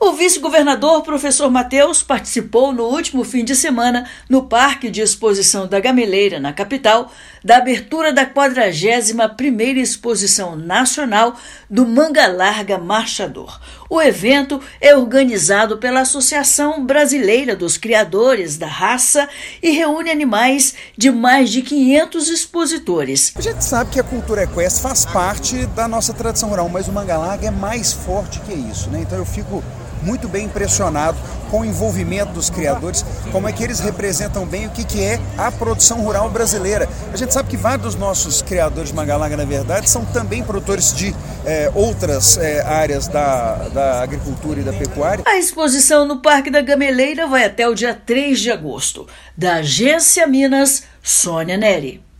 [RÁDIO] Vice-governador participa da Exposição Nacional do Mangalarga Marchador
O evento, na capital mineira, reúne mais de 500 criadores da raça. Ouça matéria de rádio.